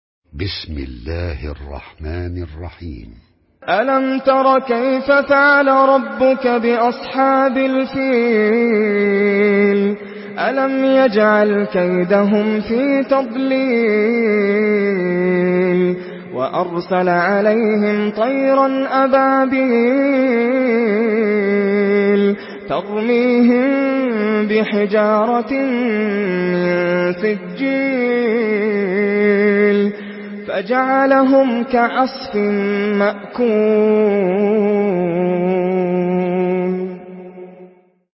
سورة الفيل MP3 بصوت ناصر القطامي برواية حفص
مرتل